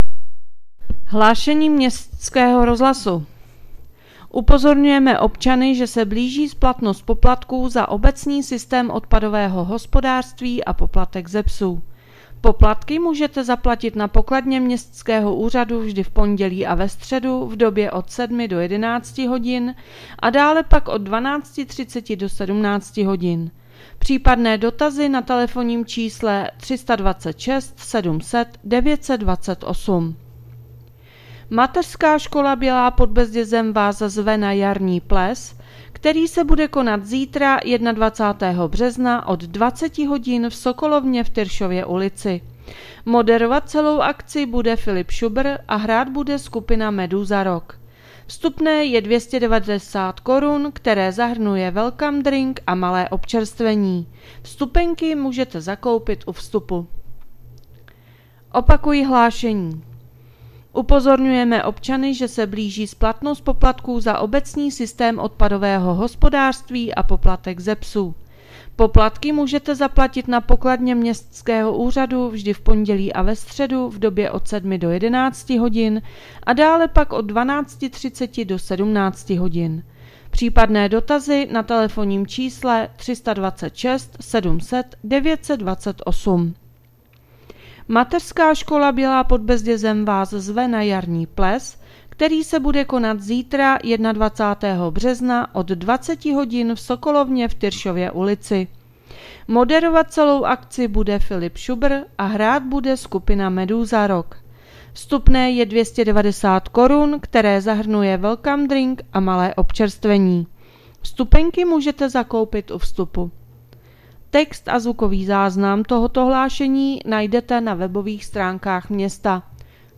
Hlášení městského rozhlasu 20.3.2026